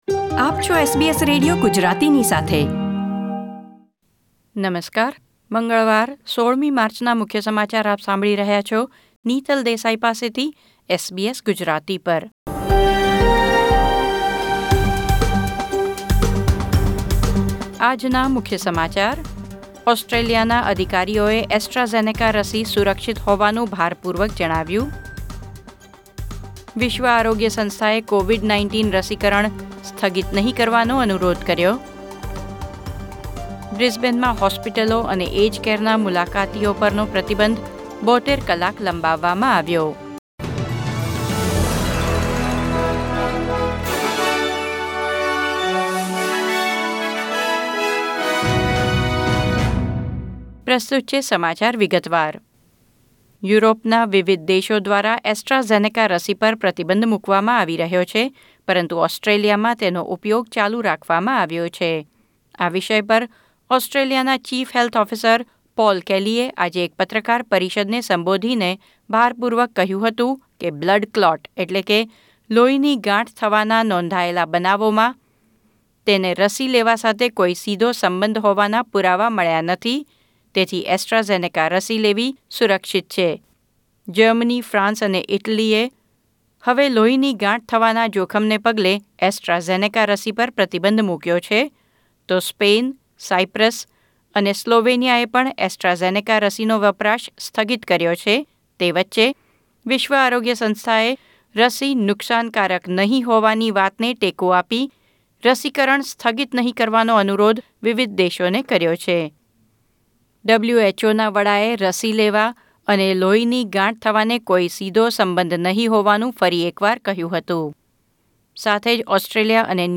SBS Gujarati News Bulletin 16 March 2021